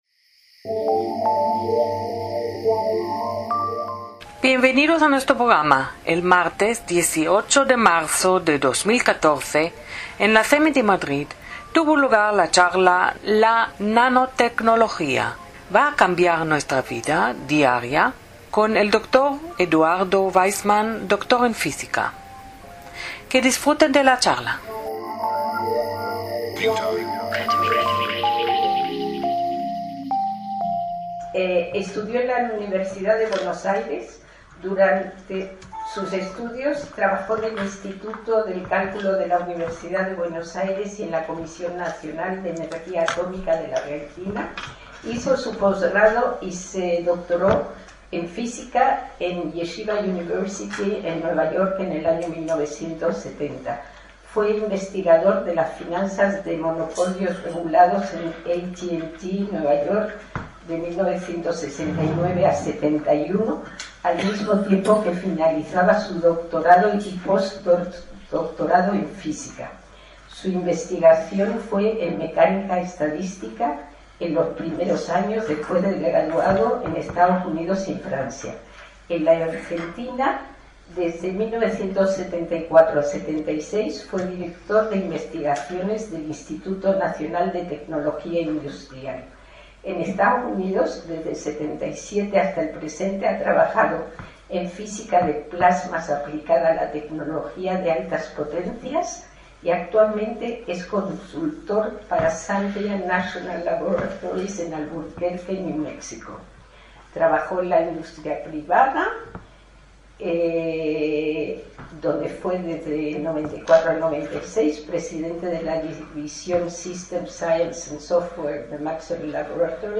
Charla “La nanotecnología
ACTOS EN DIRECTO - La nanotecnología es un campo de las ciencias aplicadas, dedicado al control y manipulación de la materia a escala de átomos y moléculas. Sus aplicaciones más prometedoras en un futuro más o menos cercano incluyen el almacenamiento, producción y conversión de energía; armamento y sistemas de defensa; producción agrícola; tratamiento de aguas; diagnóstico médico; administración de fármacos; procesamiento de alimentos; contaminación atmosférica; construcción; salud; detección y control de plagas; alimentos transgénicos; etc.